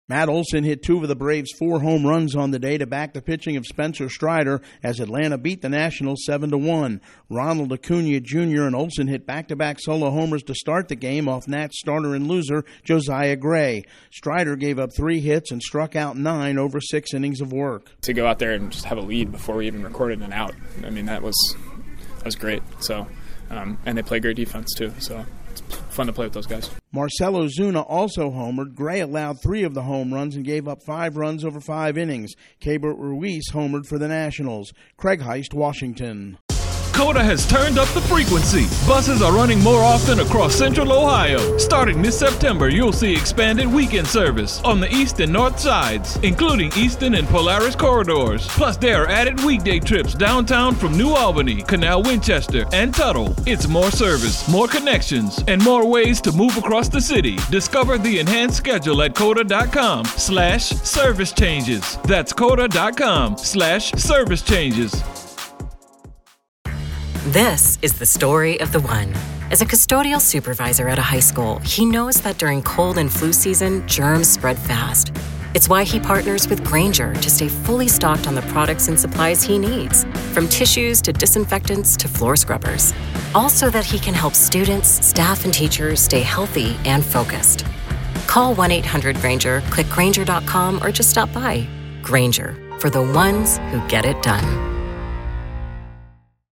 The Braves use the longball to whip the Nationals. Correspondent